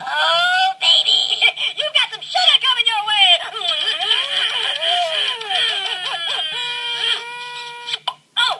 Here Comes The Sugar! is a hoops&yoyo greeting card with sound made for Valentine's Day.
Card sound